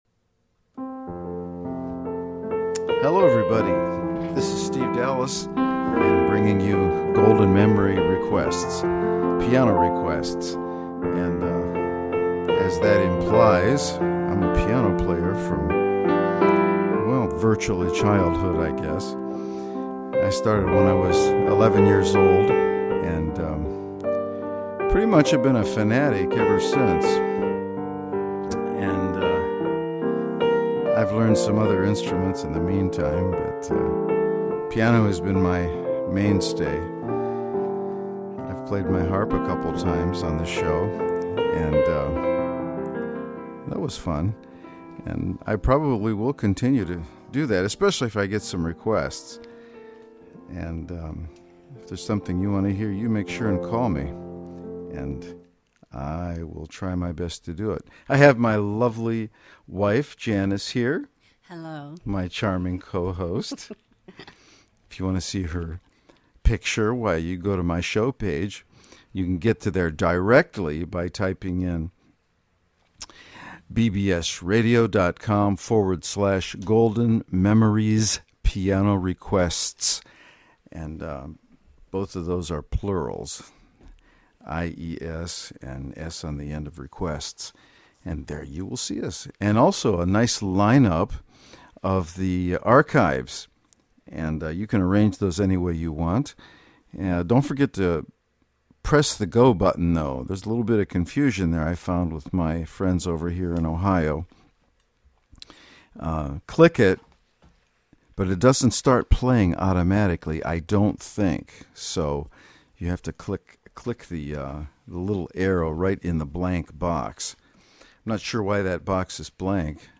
Golden oldies played to perfection!
And certainly give me a call with your song request (see listing of available numbers) and we’ll see if I can play it on the spot!